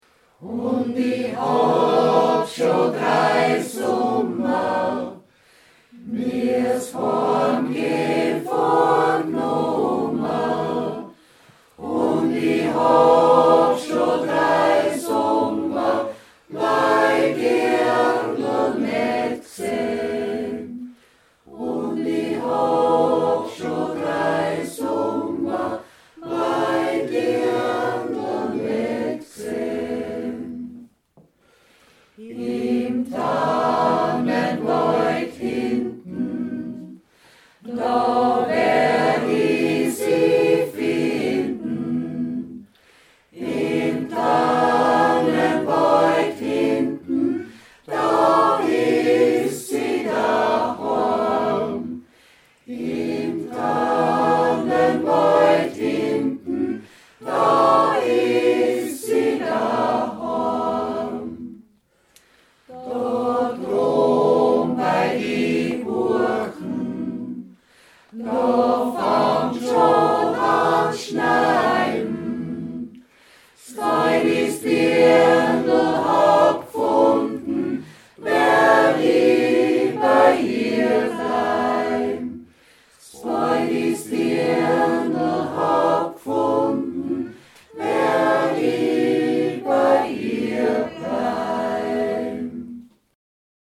XANGSMEIEREI-Probe 03.10.2016